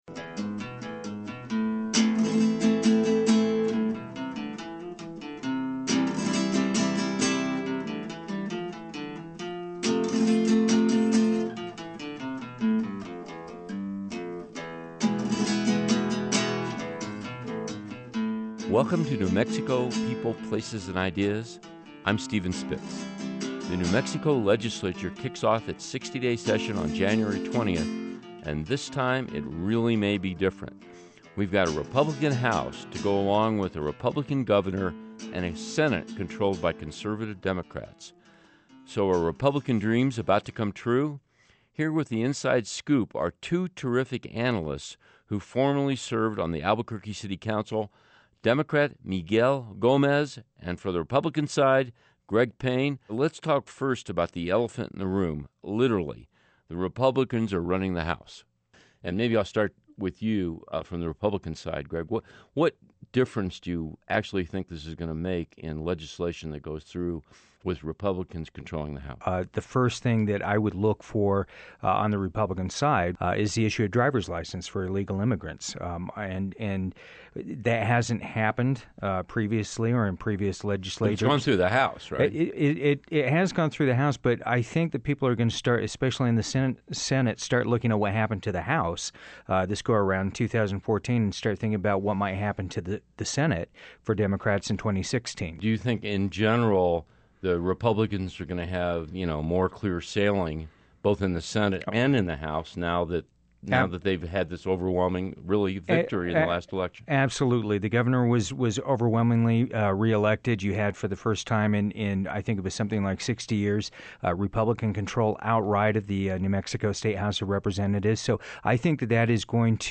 two former Albuquerque city councilors still very much in the political know